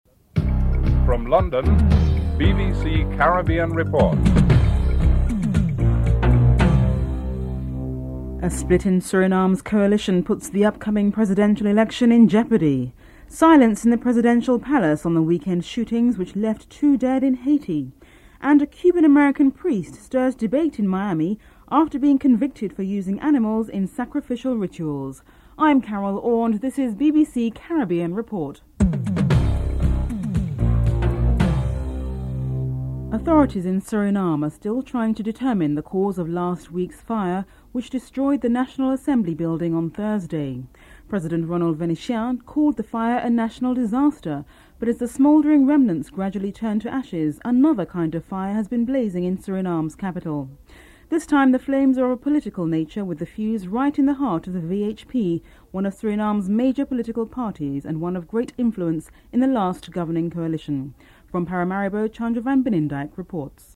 Governor Martin Bourke and Former Chief Minister Norman Saunders are interviewed.